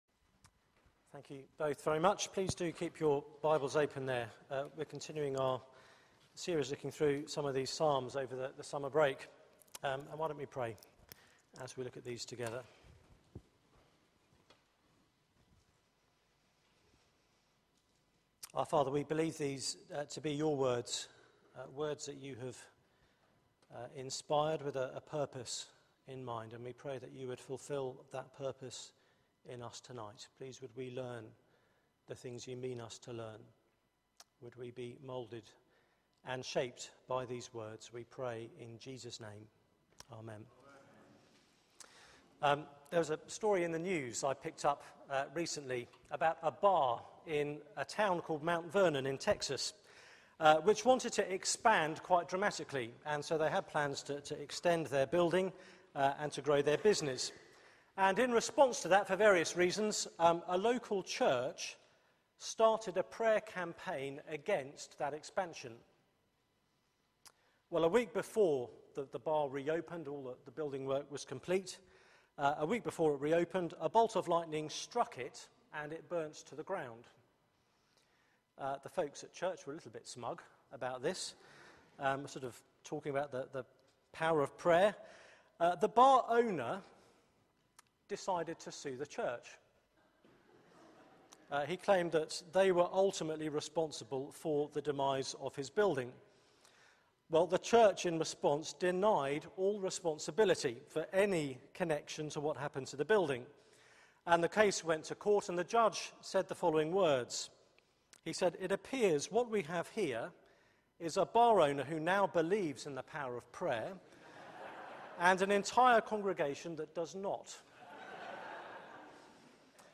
Media for 6:30pm Service on Sun 18th Aug 2013 18:30 Speaker
Passage: Psalm 20-21 Series: Summer Songs Theme: I know that the Lord saves Sermon Search the media library There are recordings here going back several years.